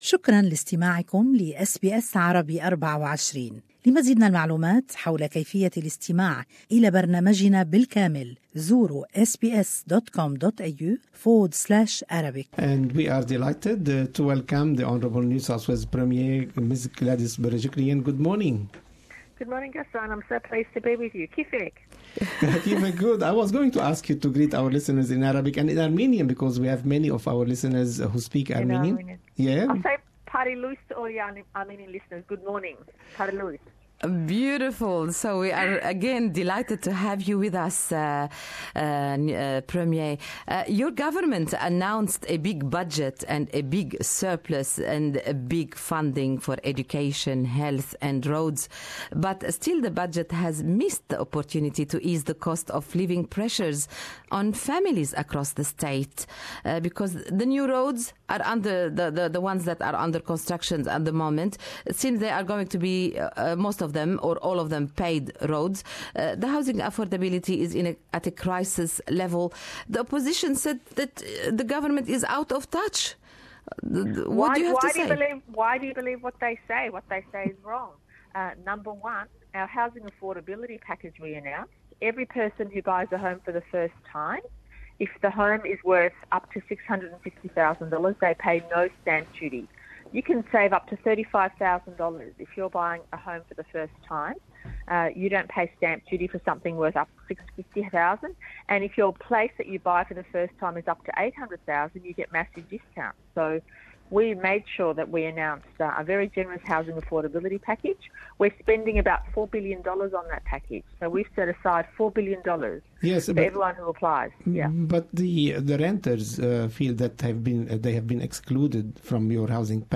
Speaking to SBS Arabic24’s Good Morning Australia, Gladys Berejiklian has defended the NSW government's housing affordability package and also said renters have been catered for, saying “more dwellings" will put downward pressure on rent prices.